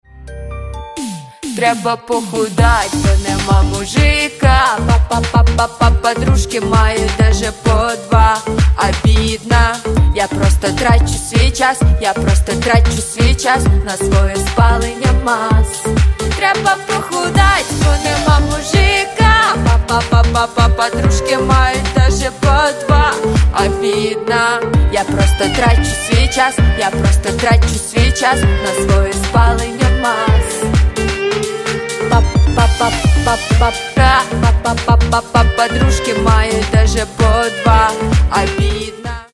Кавер И Пародийные Рингтоны
Скачать припев песни